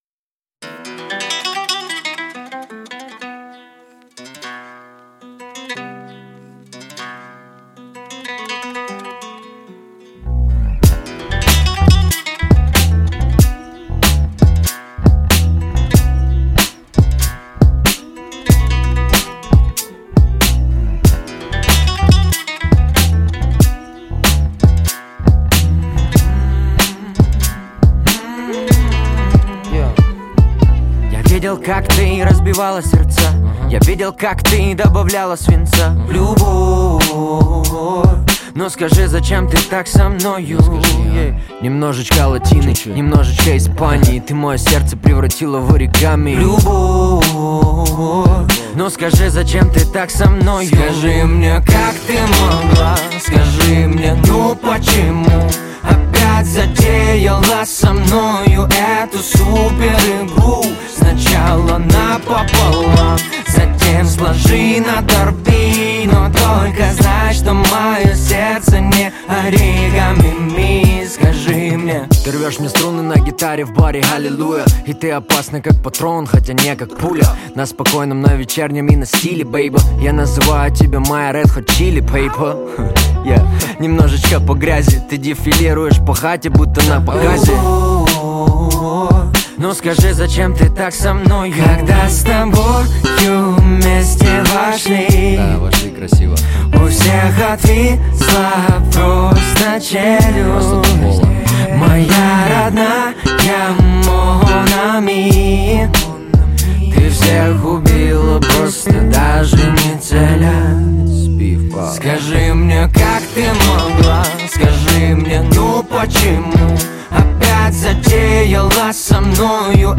Жанр: Русский рэп / R & B